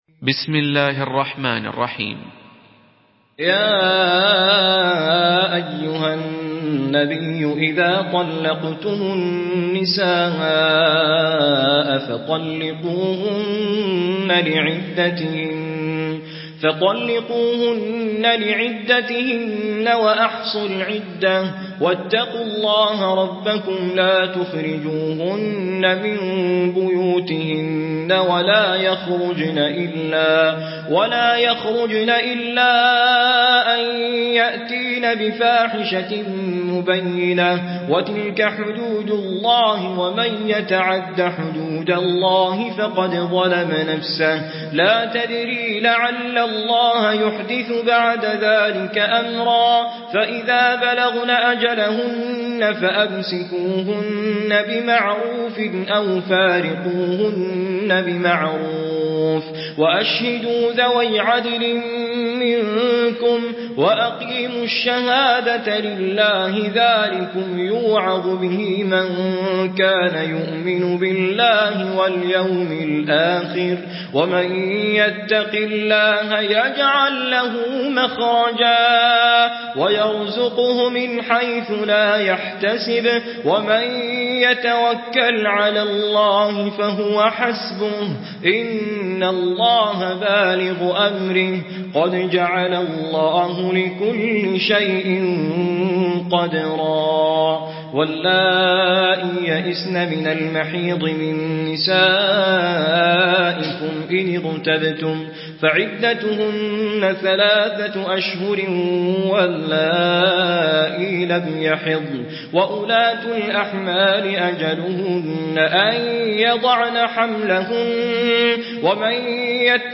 سورة الطلاق MP3 بصوت توفيق الصايغ برواية حفص
مرتل